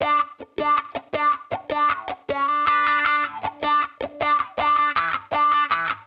Index of /musicradar/sampled-funk-soul-samples/79bpm/Guitar
SSF_StratGuitarProc2_79E.wav